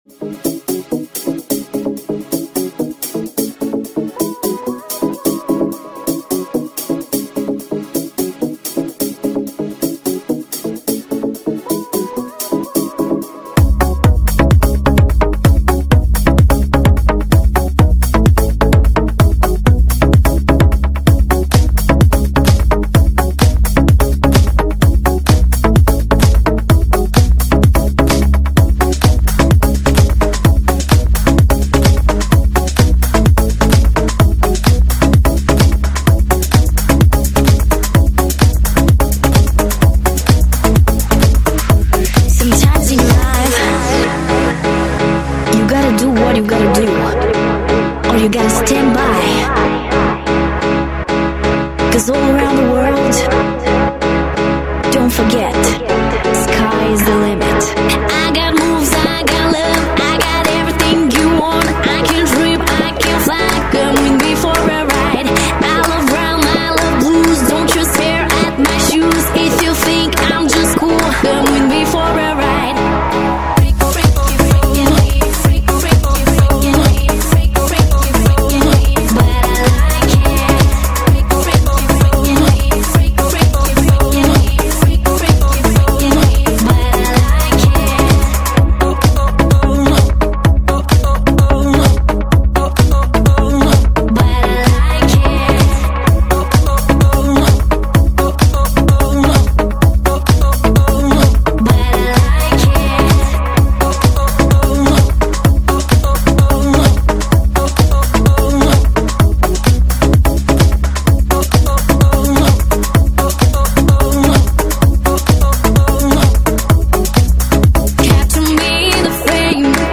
Жанр:Dance